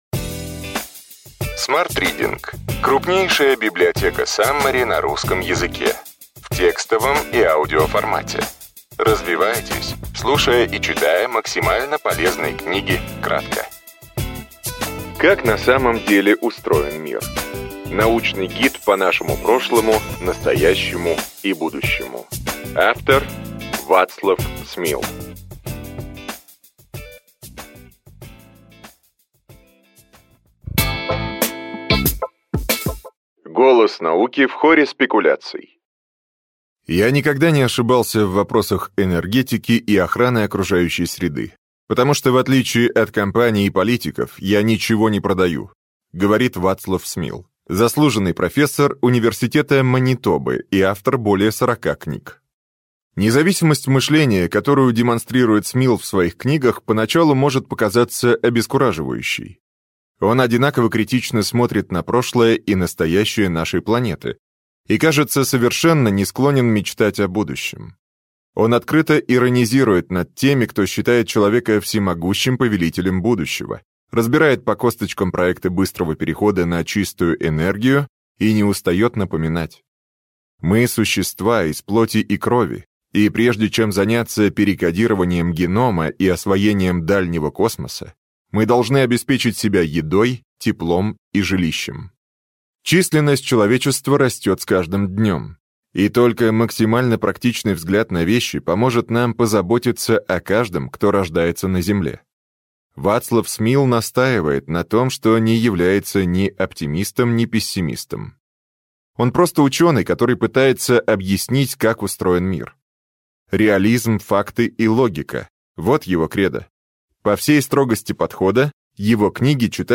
Аудиокнига Ключевые идеи книги: Как на самом деле устроен мир. Научный гид по нашему прошлому, настоящему и будущему.